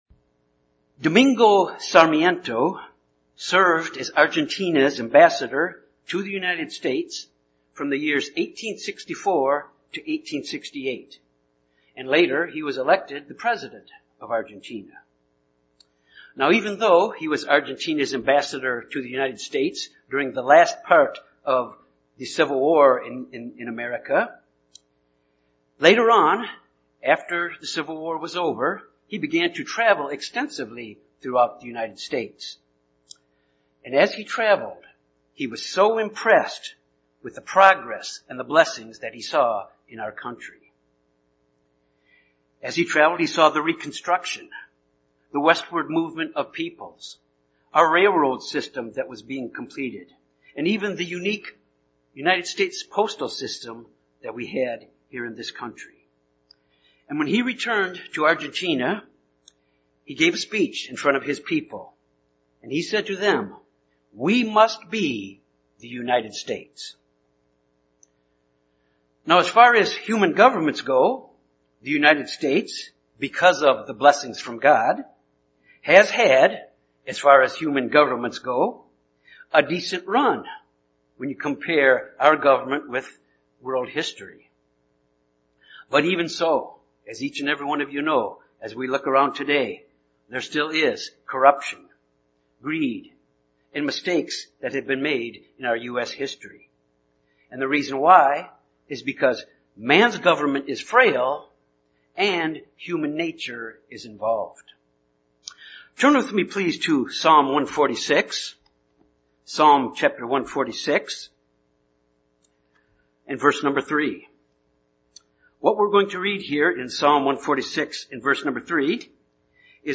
Sermons
Given in Jonesboro, AR Little Rock, AR